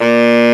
Index of /m8-backup/M8/Samples/Fairlight CMI/IIX/REEDS
BARISAX2.WAV